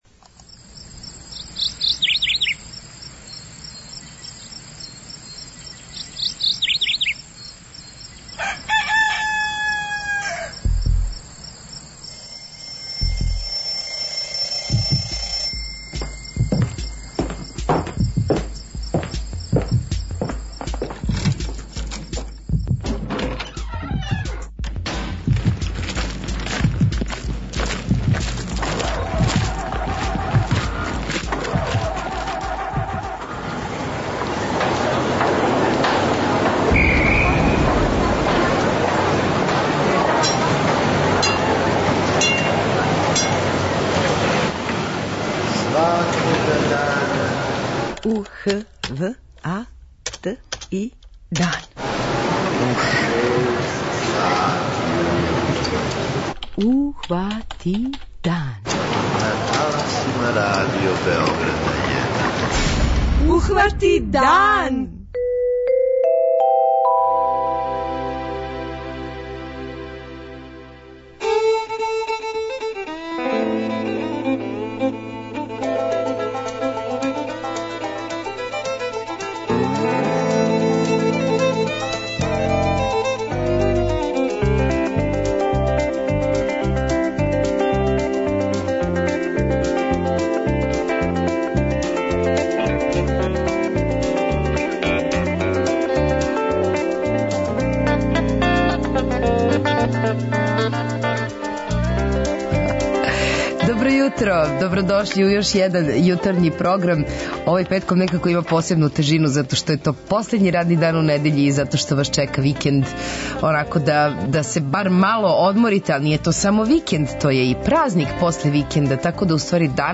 преузми : 43.14 MB Ухвати дан Autor: Група аутора Јутарњи програм Радио Београда 1!